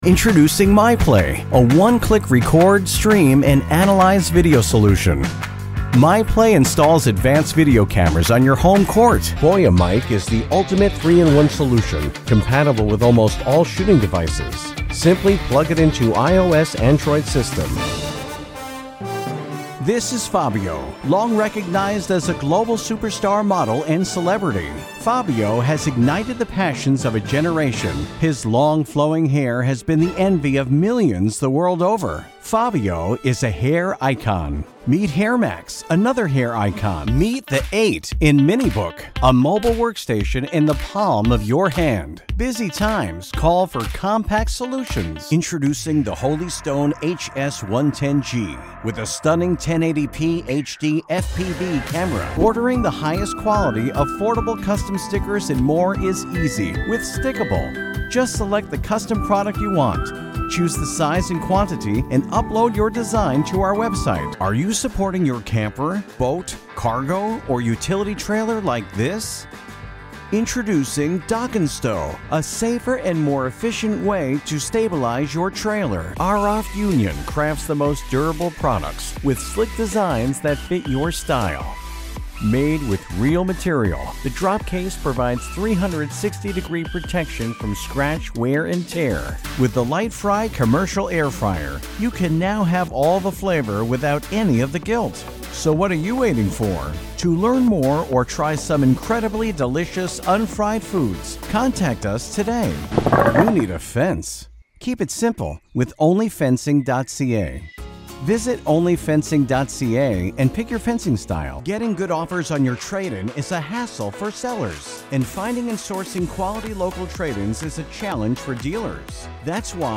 Vídeos Explicativos
My style of VO is bright, clear and articulate.
My voice can be described as clear, friendly, personable, confident, articulate, and smooth.
Sennheiser 416 mic